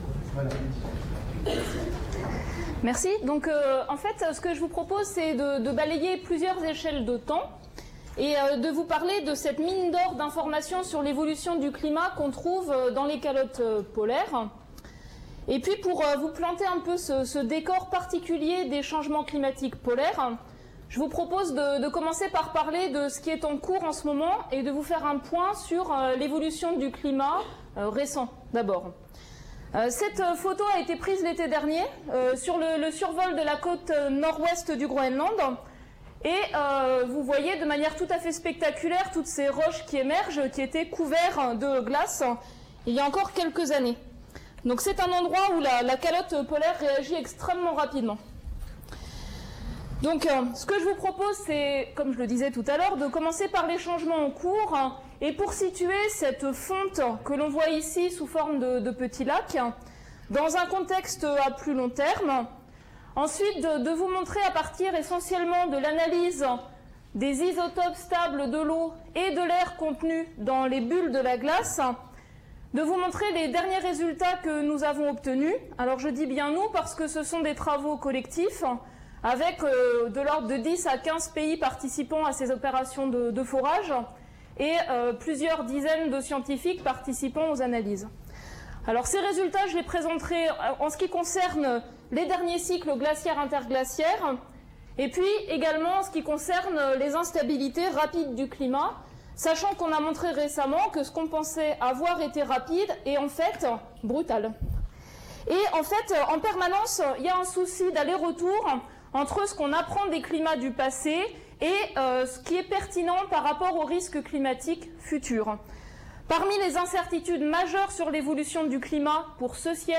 Écouter la conférence Glaces polaires : hier, aujourd'hui, demain....